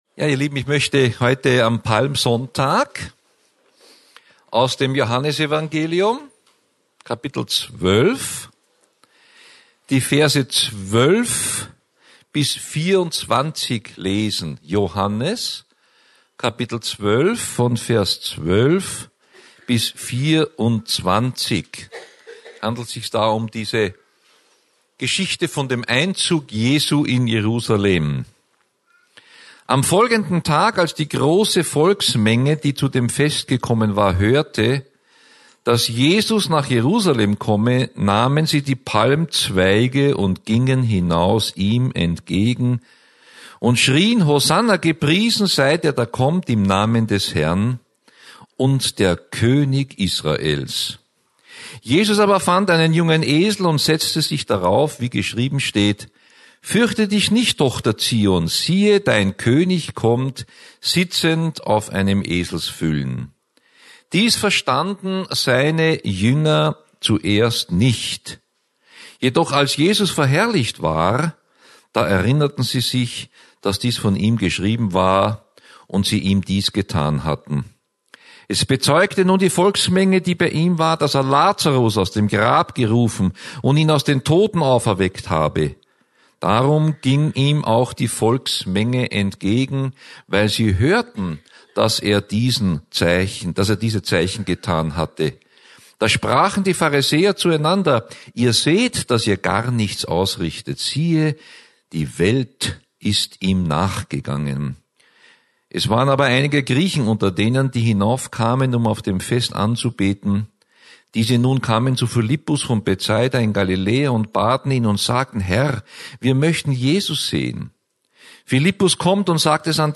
Hier finden Sie das Predigt Archiv für das Jahr 2015.